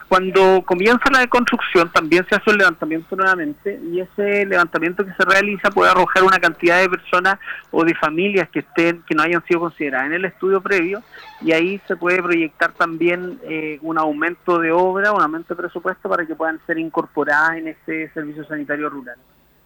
En conversación con La Radio, el alcalde de la comuna, Cristian Navarrete, señaló que las obras beneficiarán a 89 domicilios y contempla un tanque elevado de 75 metros cúbicos, además de un generador para evitar cortes en el suministro.
cuna-1-alcalde-paillaco.mp3